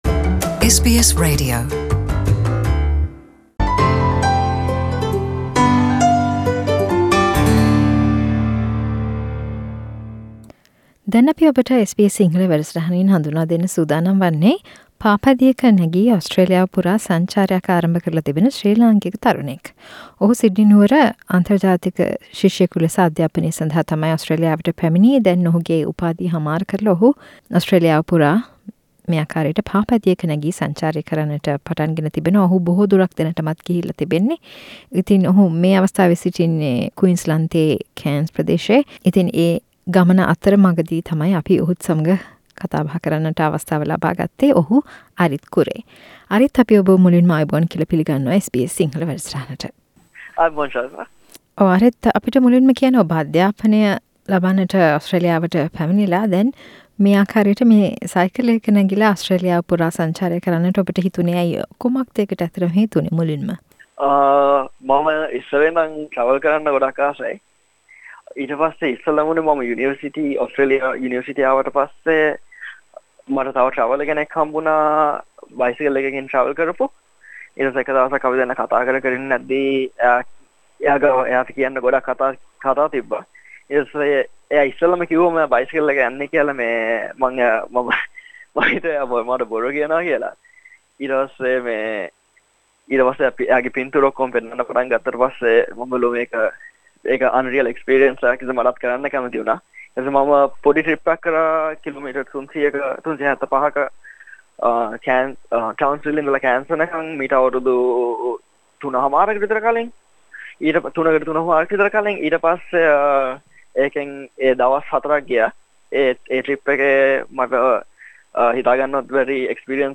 A chat